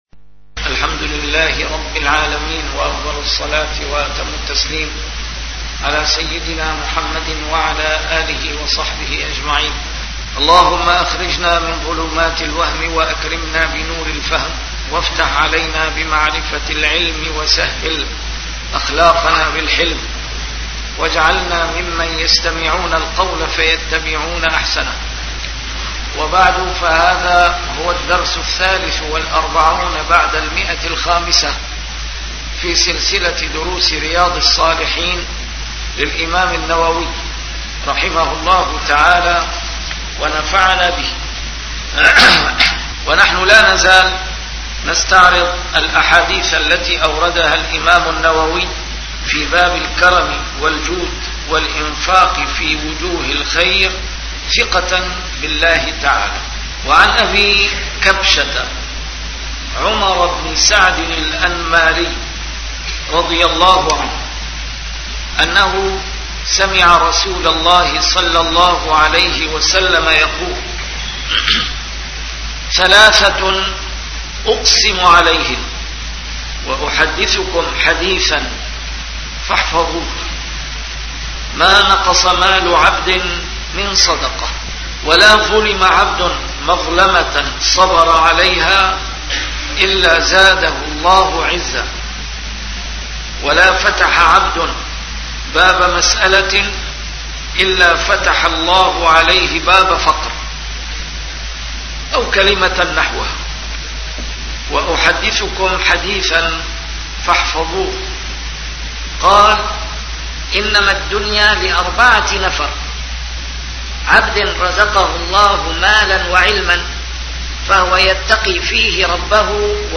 A MARTYR SCHOLAR: IMAM MUHAMMAD SAEED RAMADAN AL-BOUTI - الدروس العلمية - شرح كتاب رياض الصالحين - 543- شرح رياض الصالحين: الكرم والجود